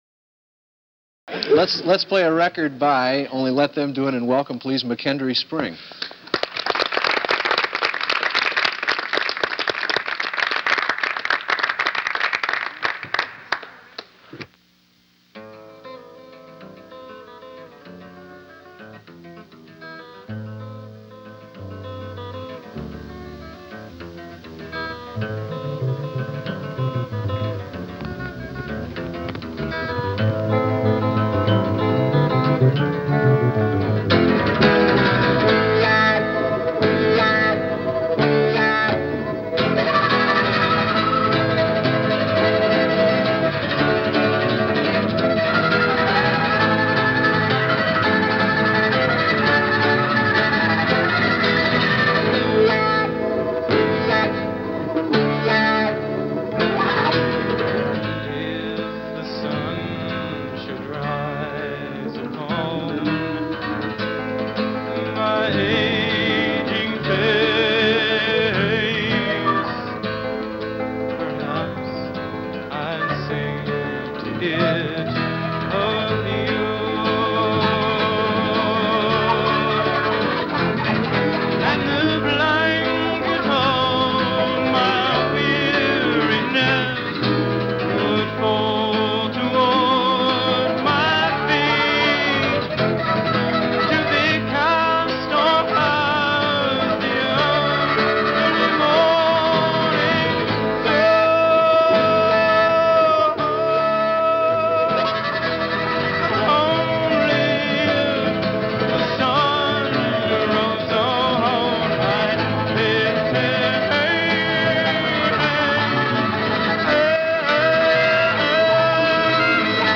A live session tonight from early American Prog.